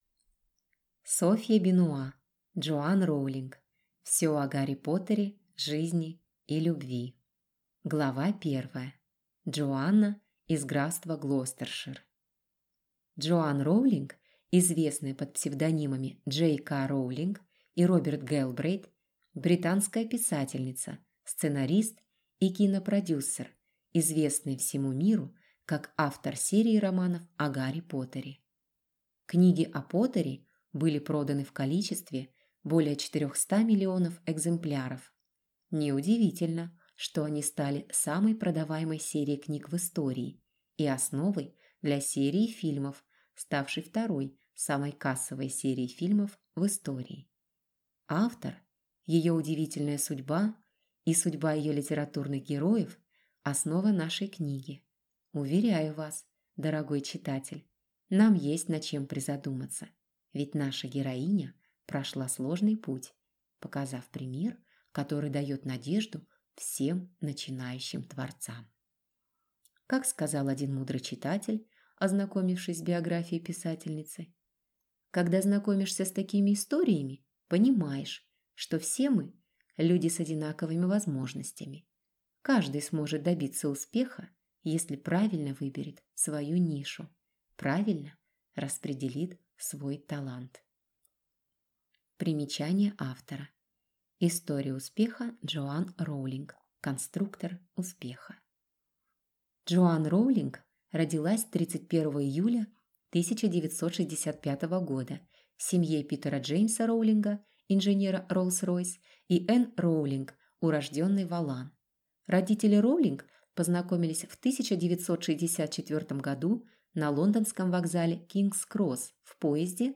Аудиокнига Джоан Роулинг. Всё о Гарри Поттере, жизни и любви | Библиотека аудиокниг